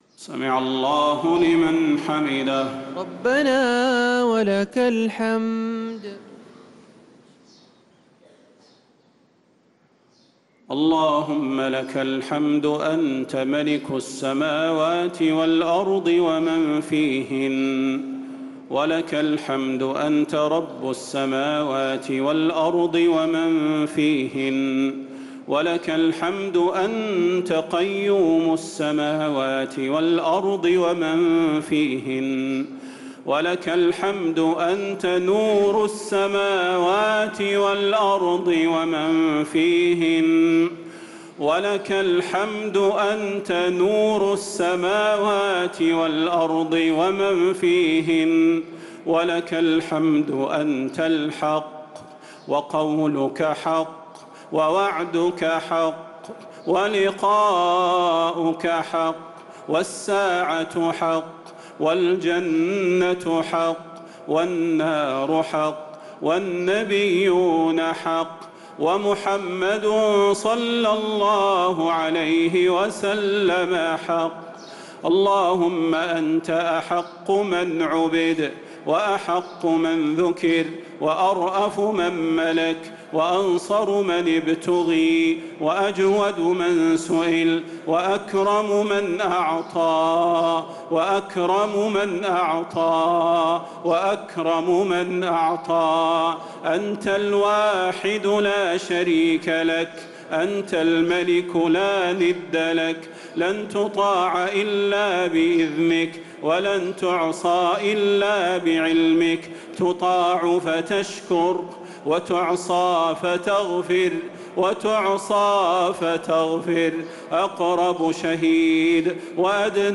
صلاة التراويح ليلة 21 رمضان 1445 للقارئ صلاح البدير - دعاء القنوت